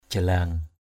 /ʥa-la:ŋ/